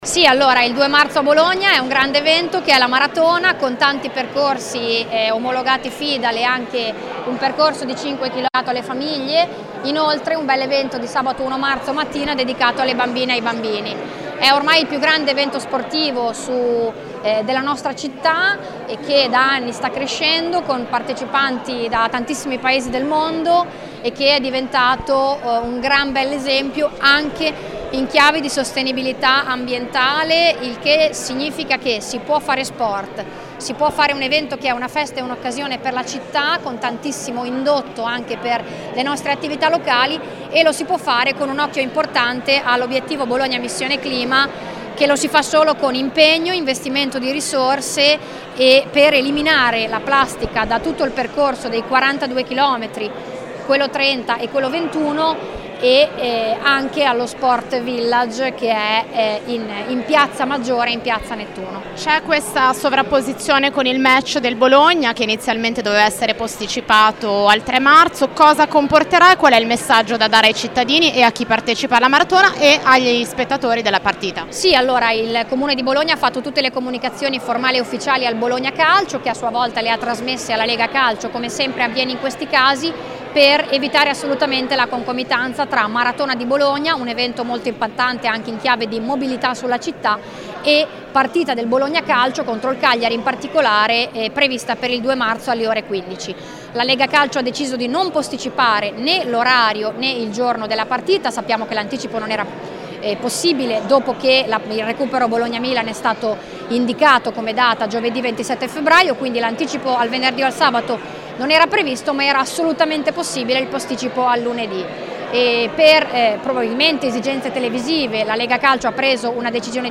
alla conferenza stampa di presentazione dell’evento:
BOLOGNA-MARATHON-2025-CONFERENZA-STAMPA.mp3